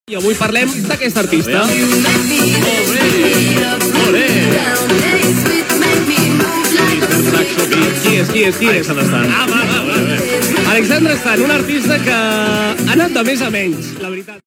Secció "TBT" portant un tema musical del passat
Musical